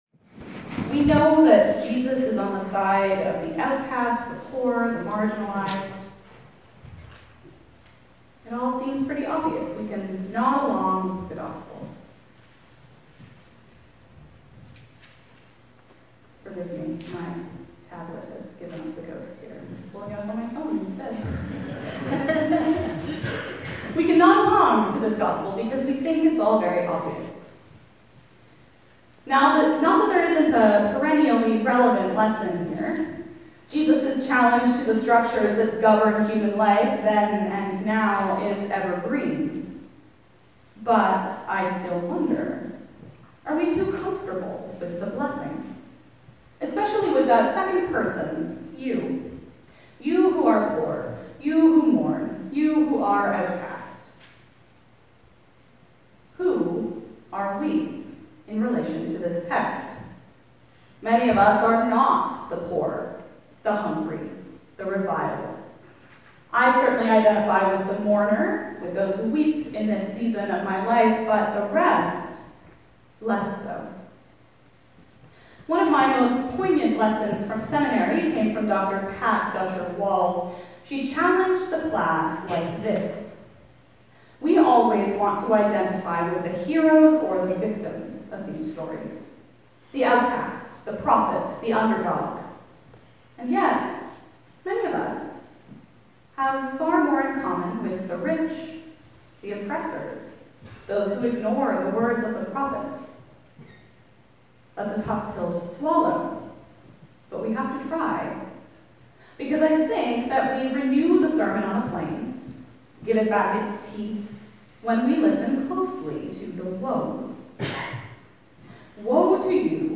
Sermon 16 February 2025